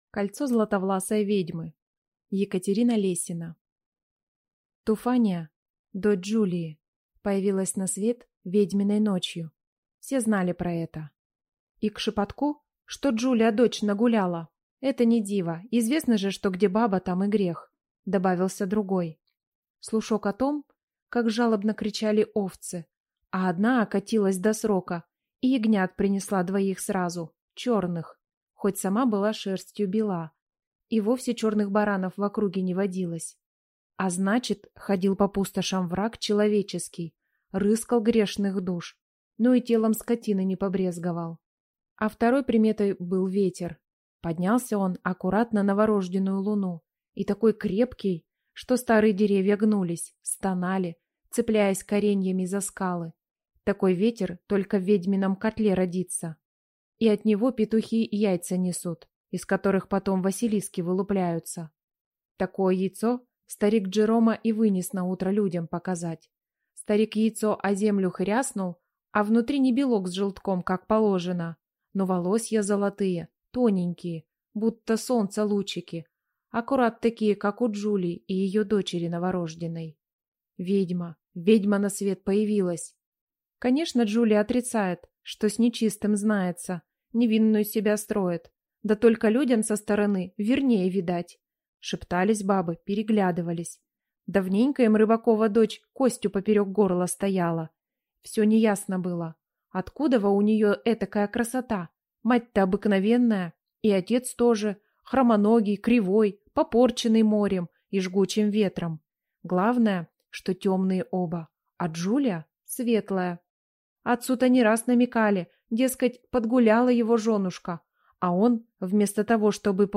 Аудиокнига Кольцо златовласой ведьмы | Библиотека аудиокниг